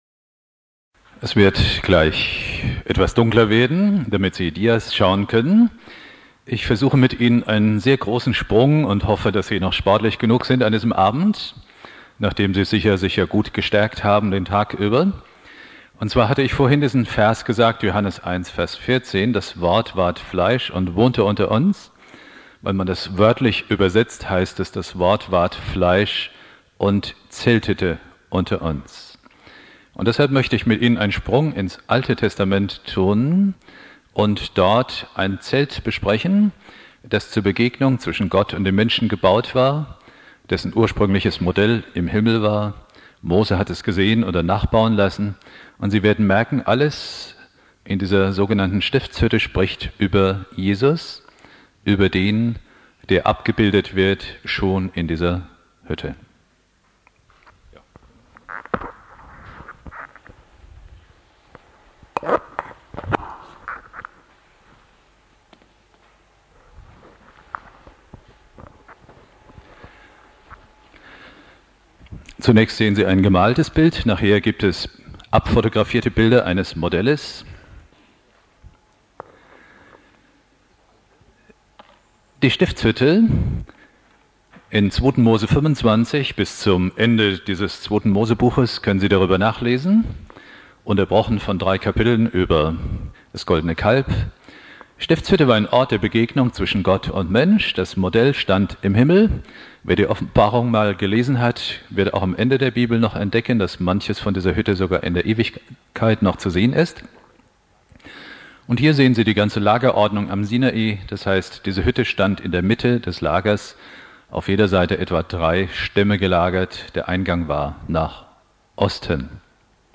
Predigt
1.Weihnachtstag